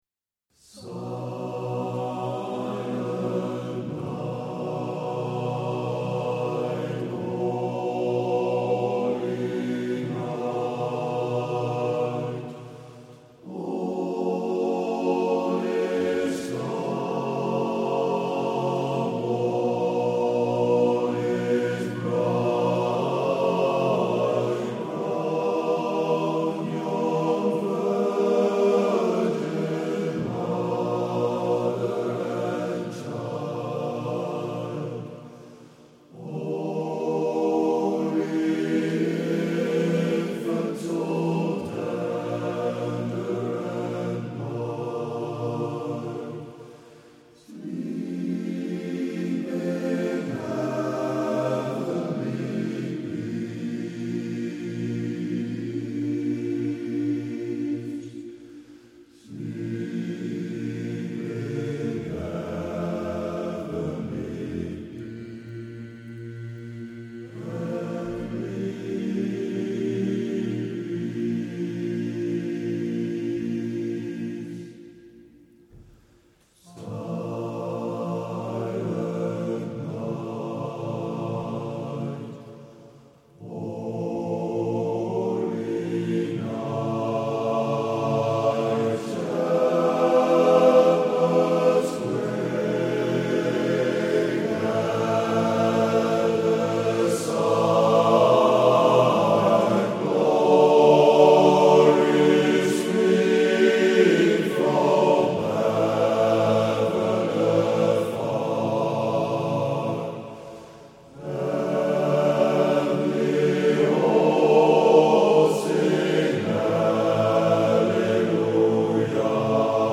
Heel lastig was het om goede uitvoeringen in barbershopstijl te vinden.
Silent-Night-Barbershop.mp3